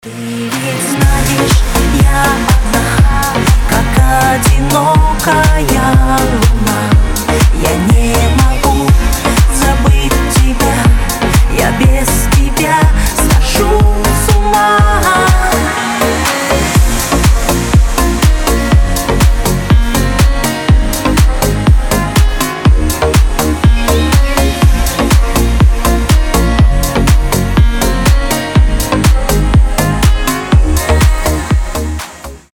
• Качество: 320, Stereo
поп
женский вокал
дуэт